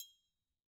Triangle3-HitFM_v1_rr1_Sum.wav